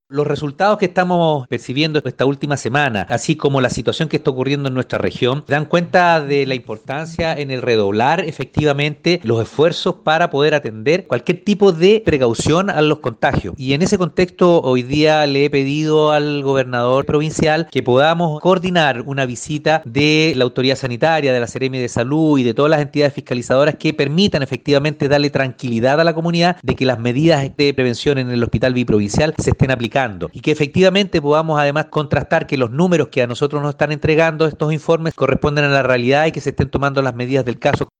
02-ALCALDE-SUBROGANTE-Gestión-con-Gobernación.mp3